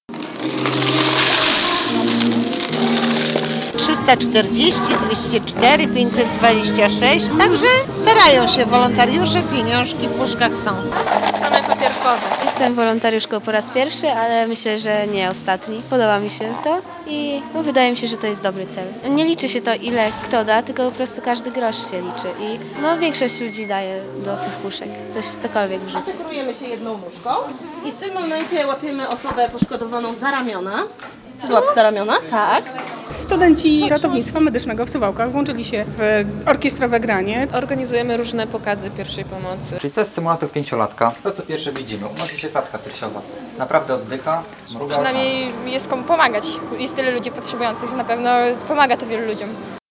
WOŚP w Suwałkach - relacja